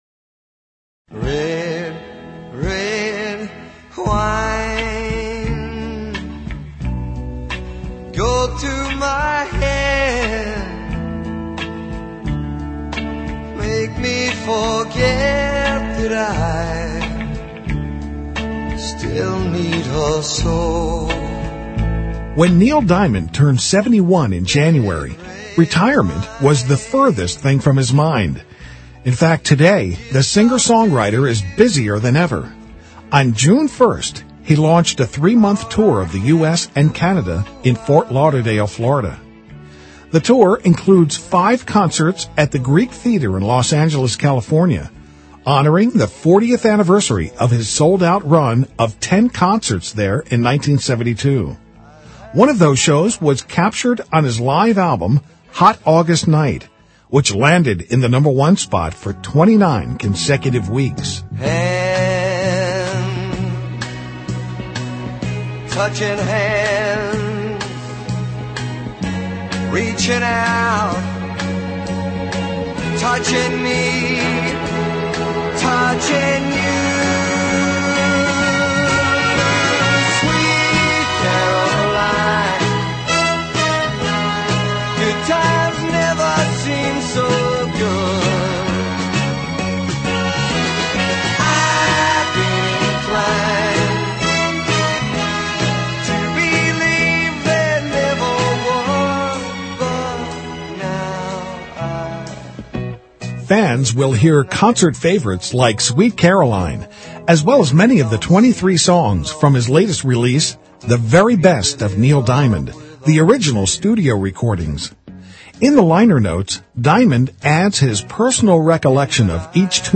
report on Neil Diamond